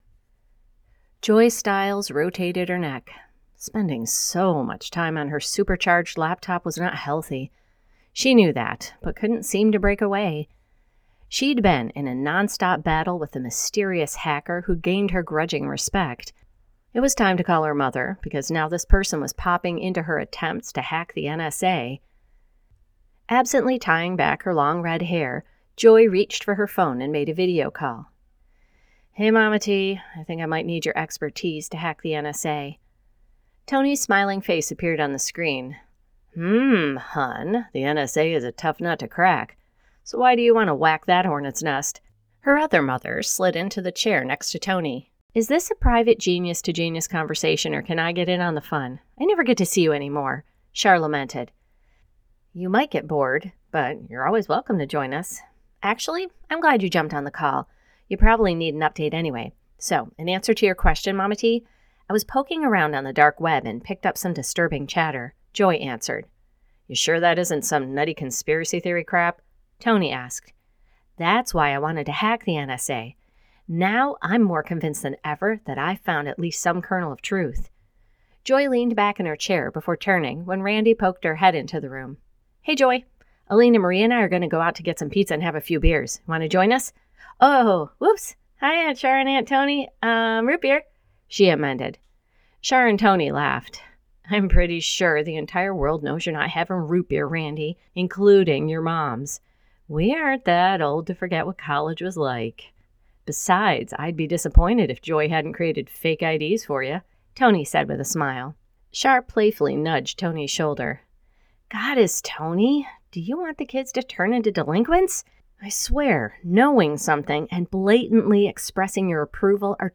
Love Hacks by Annette Mori Book II in The Next Generation Series [Audiobook]